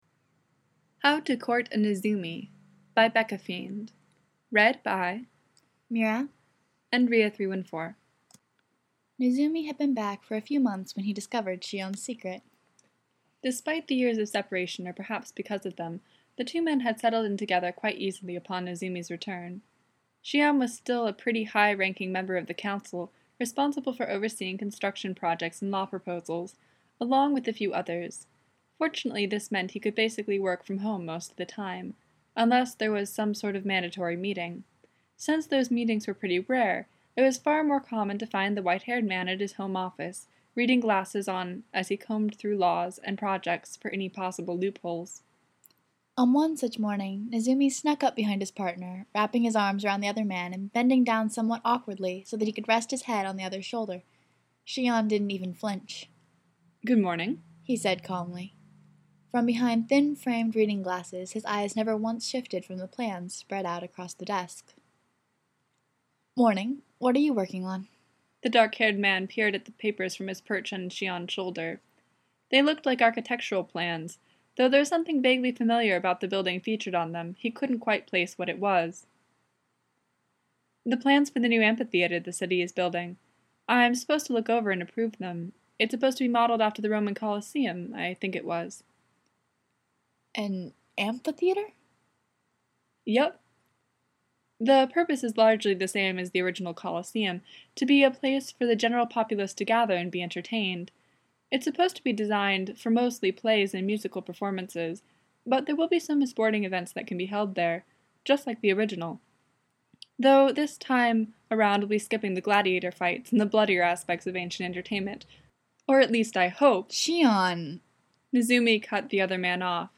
collaboration|two voices | Audiofic Archive